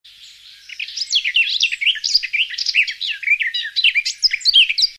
Рингтоны » звуки животных » Переливы птиц